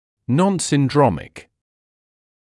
[ˌnɔnsɪn’drɔmɪk][ˌнонсин’дромик]не имеющий синдрома; несиндромный